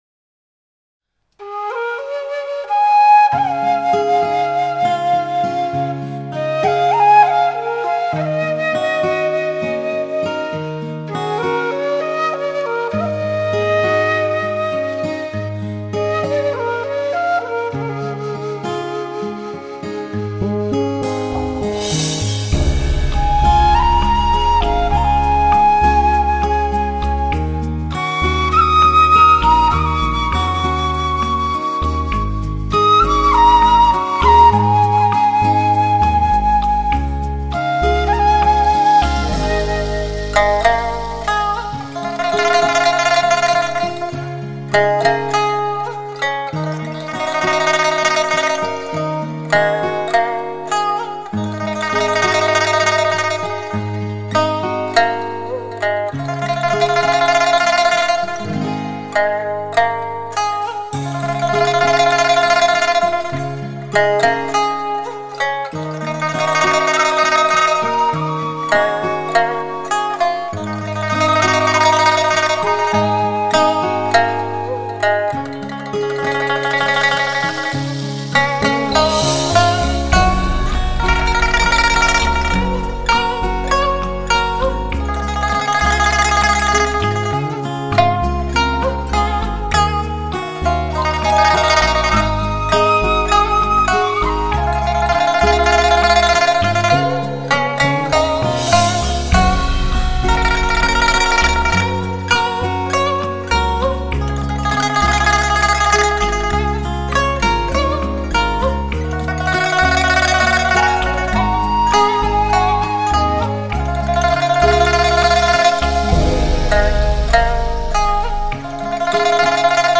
中国民乐与德国黑胶唱片技术的世纪接轨！
琵琶